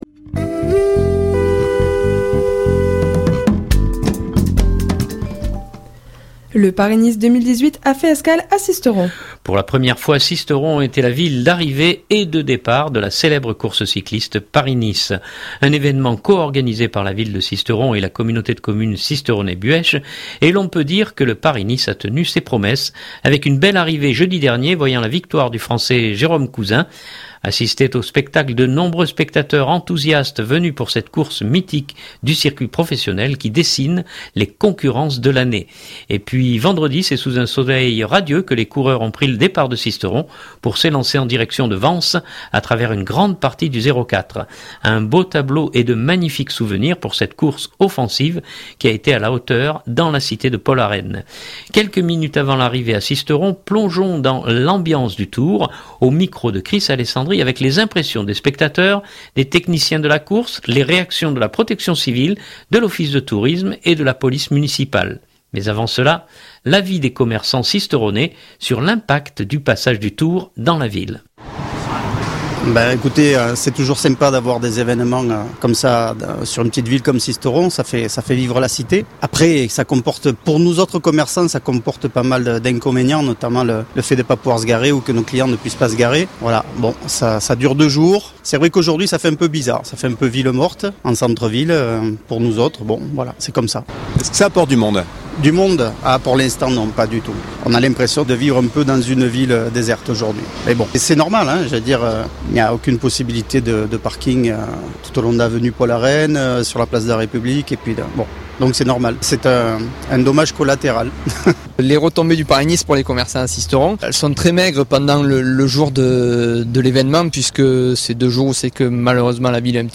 Quelques minutes avant l’arrivée à Sisteron, plongeons dans l’ambiance du tour